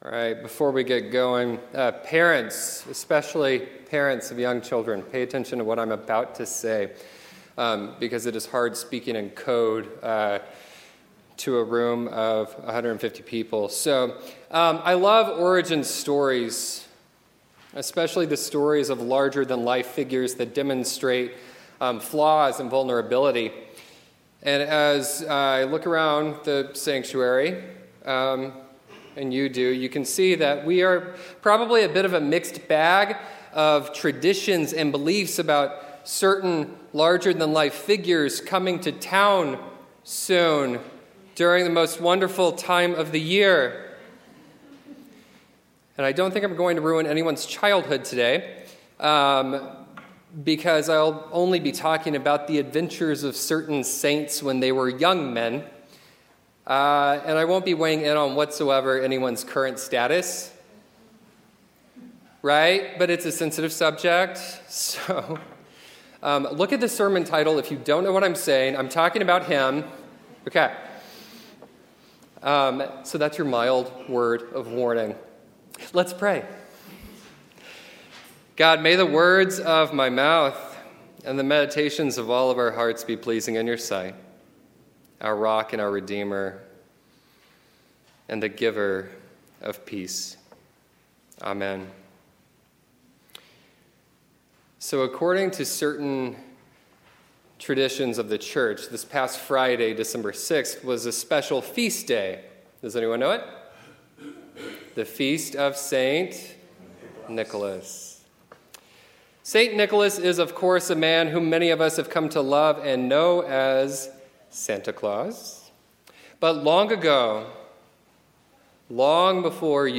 Note: the affirmation of faith was recited before the reading, using the words of the Nicene Creed.
Scripture Lesson